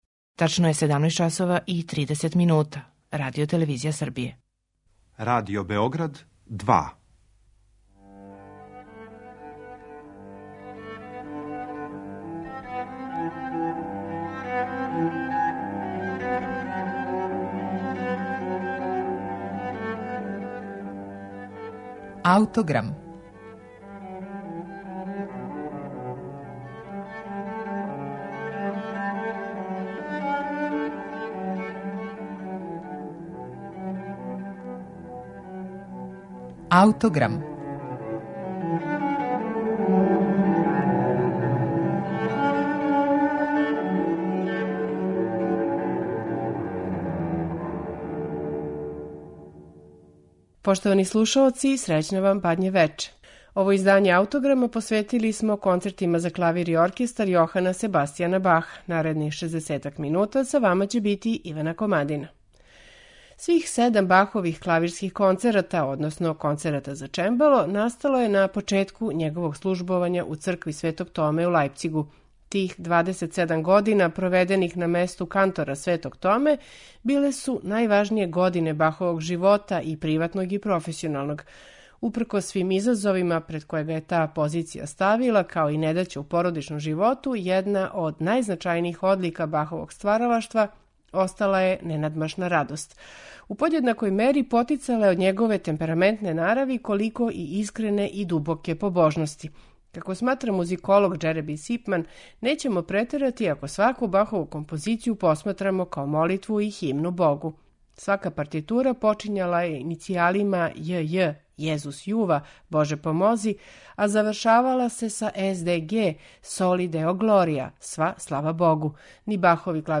Бахови концерти за клавир и оркестар број 1, 2 и 4